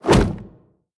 shield_hit.ogg